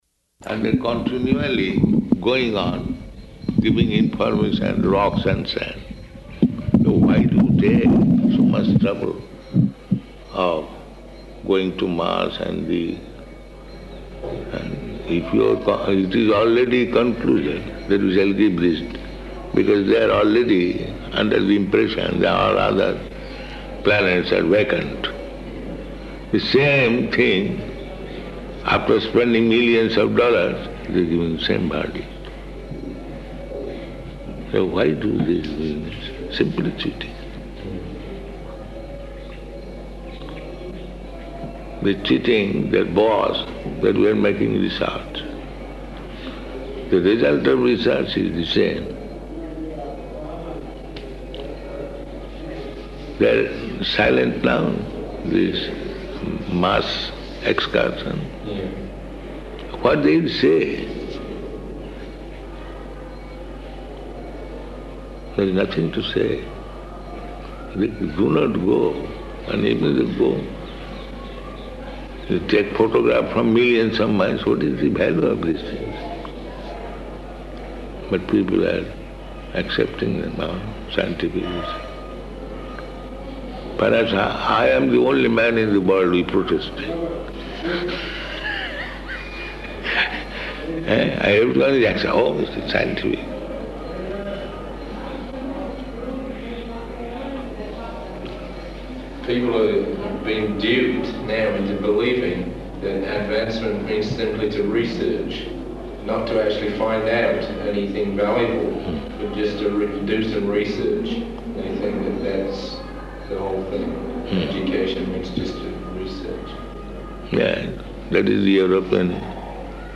Room Conversation
Location: Vṛndāvana